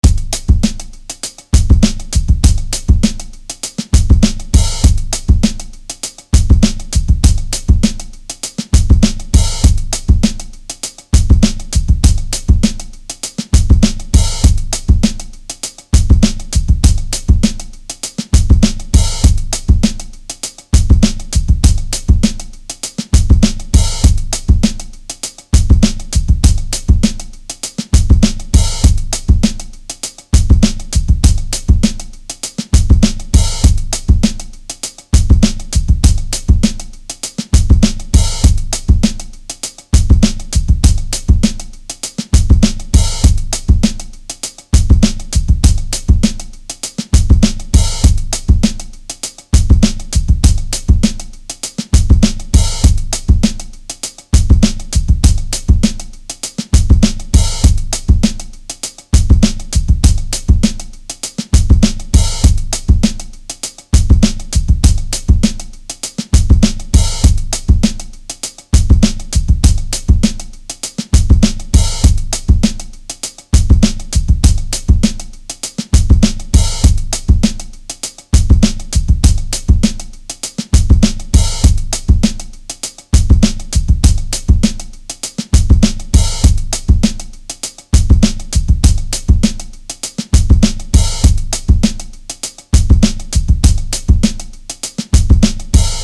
Beats